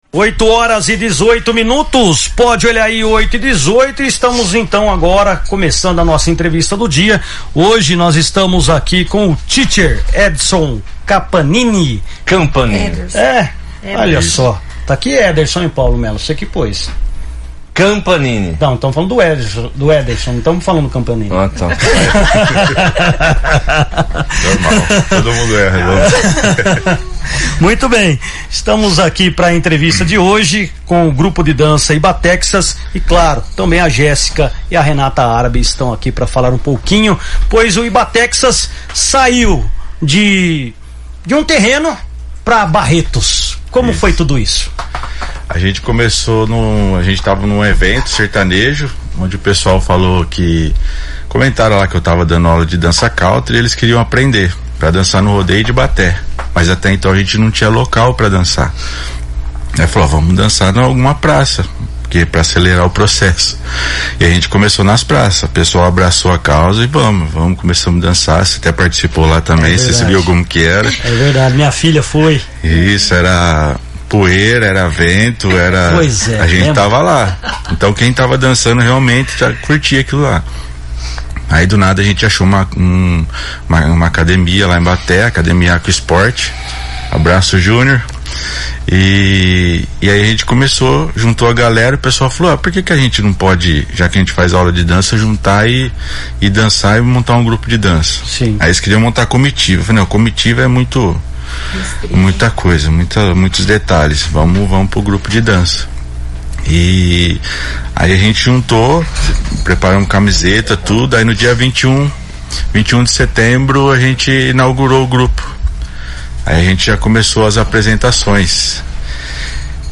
Integrantes do Grupo de Dança Ibatexas participam de entrevista na São Carlos FM |